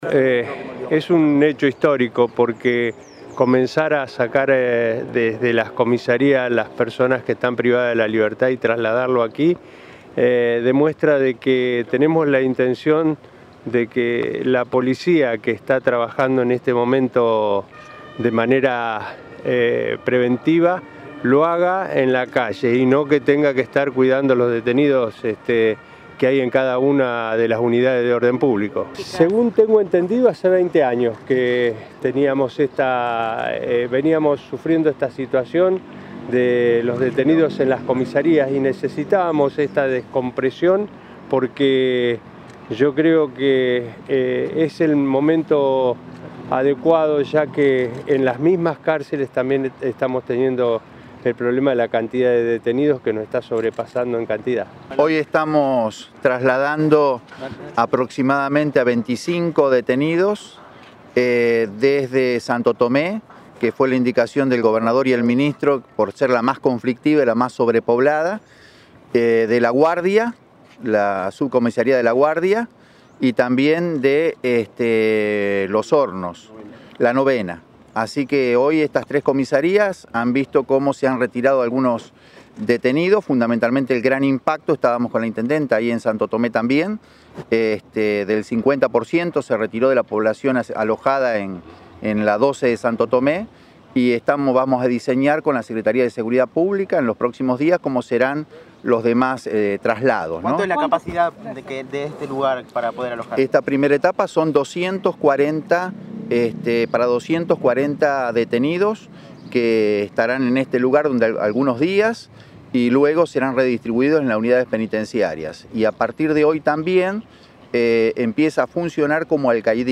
Rubén Rimoldi, ministro de Seguridad de la provincia, y Walter Gálvez, secretario de Asuntos Penales